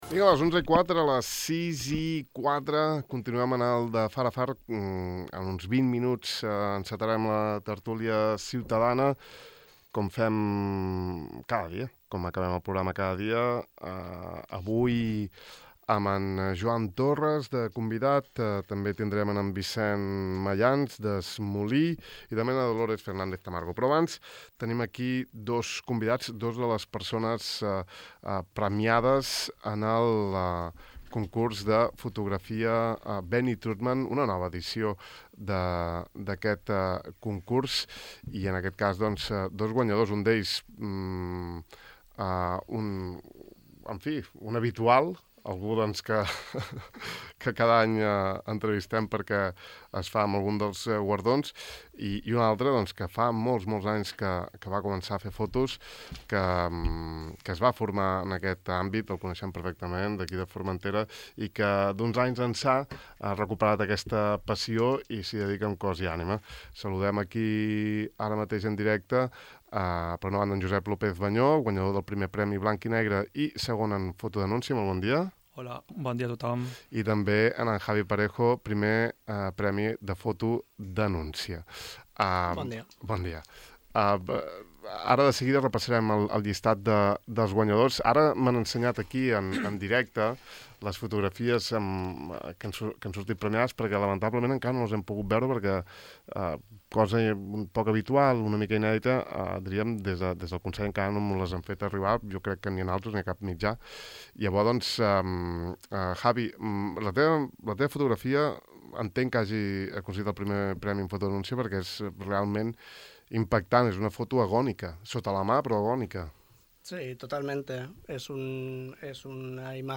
Aquí podeu veure la relació amb tots els premiats i més avall teniu l’enllaç per escoltar l’entrevista sencera: